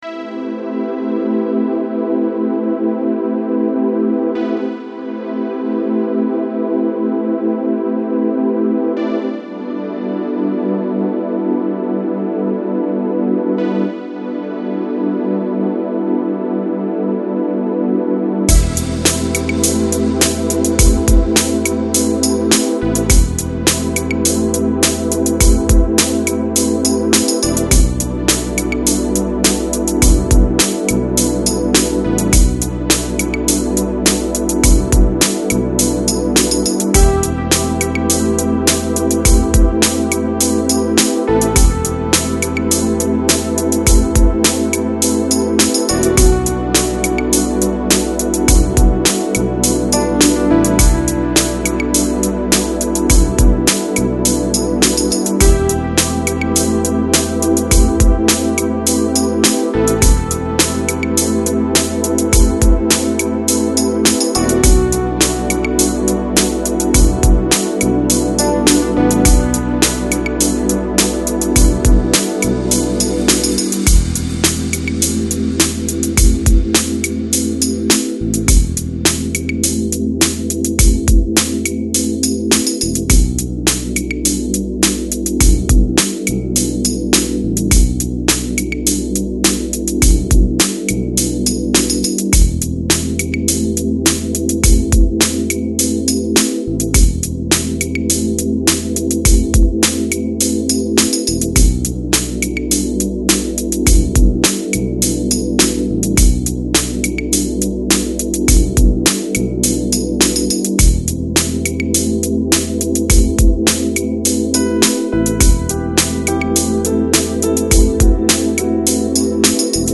Жанр: Lounge, Chill Out, Downtempo, Lo Fi